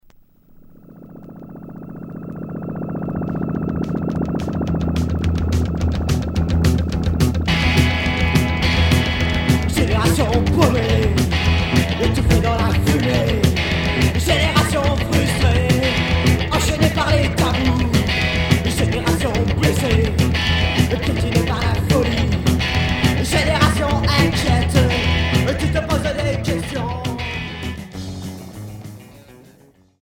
Rock punk Unique 45t retour à l'accueil